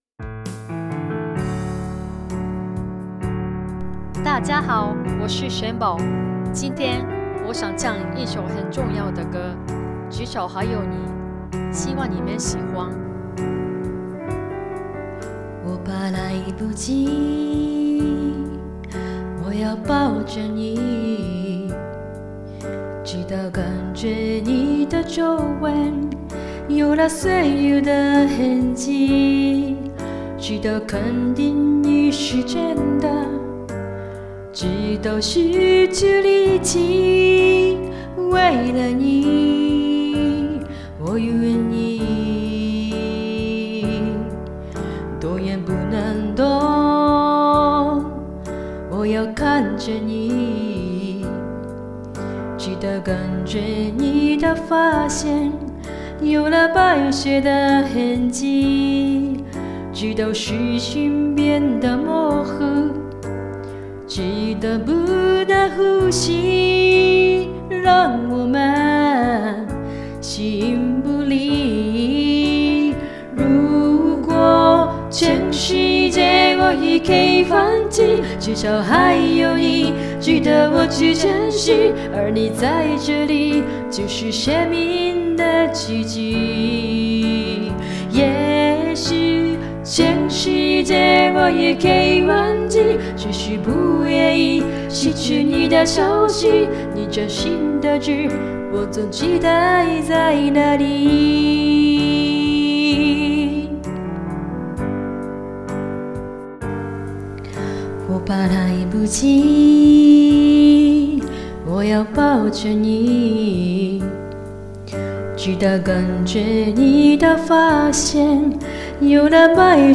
歌唱コンテスト結果発表
・吐字清晰，旋律优美
・和音部分特别美，前面的台词也说得好
・吐字清晰，音准标准，旋律优美，还自己配了副歌，很专业
・咬字很清楚；声线很好听；节奏感很不错。我觉得可以出唱片了     ～～
・很用心，录音效果好。因为加了自我介绍，和和声，我觉得创意     真的很加分了。